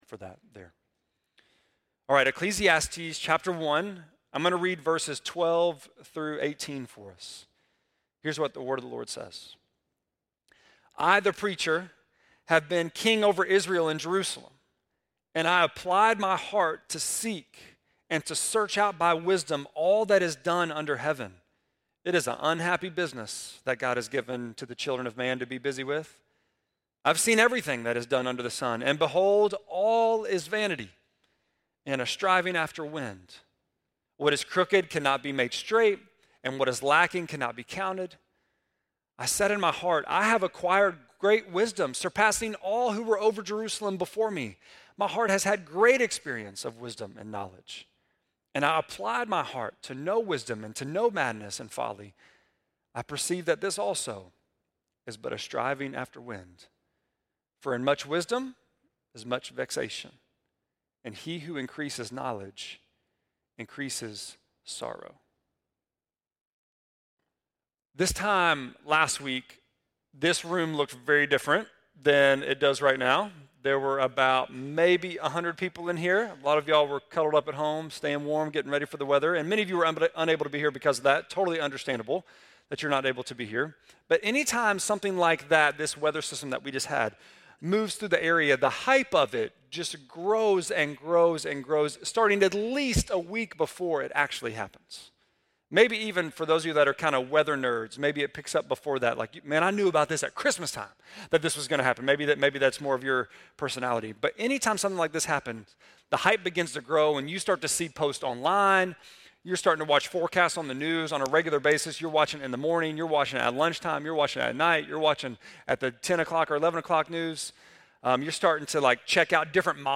1.12-sermon.mp3